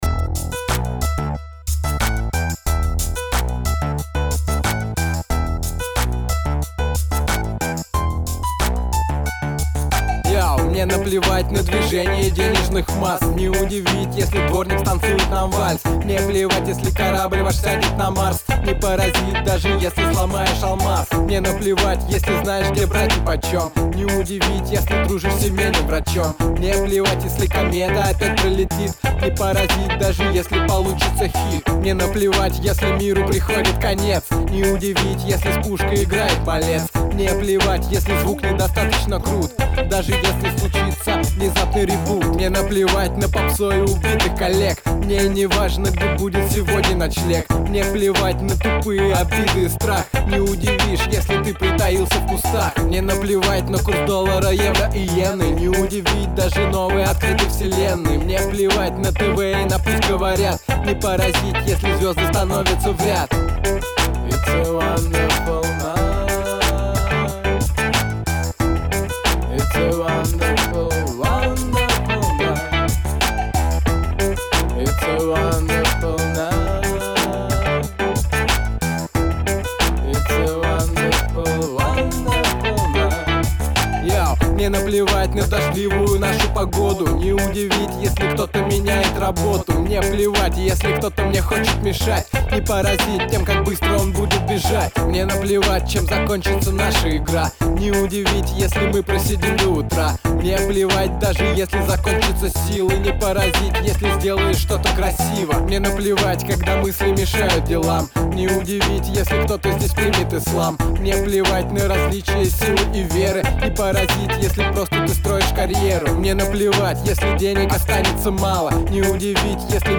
Русский Rap - авторское творчество